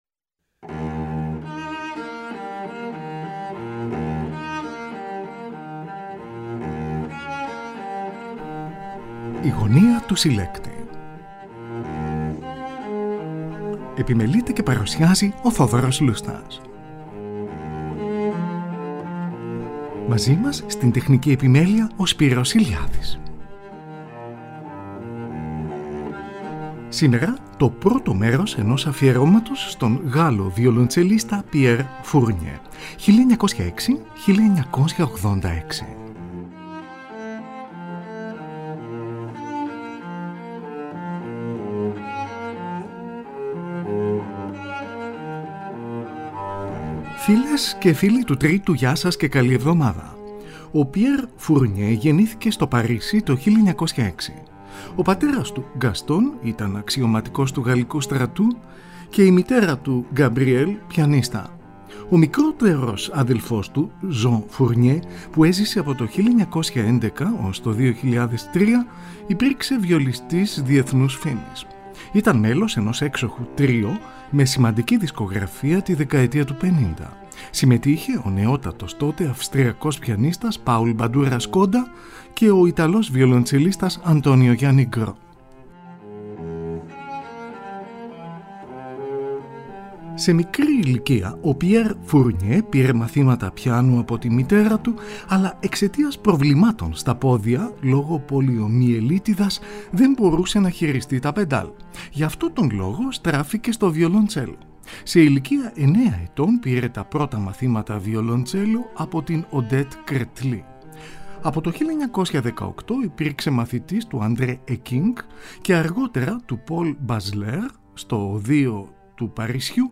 ΑΦΙΕΡΩΜΑ ΣΤΟΝ ΓΑΛΛΟ ΒΙΟΛΟΝΤΣΕΛΙΣΤΑ PIERRE FOURNIER (1906-1986) – Α’ ΜΕΡΟΣ
Eρμηνεύει έργα των François Couperin του Μεγάλου, Johann Sebastian Bach, Camille Saint-Saëns και Robert Schumann.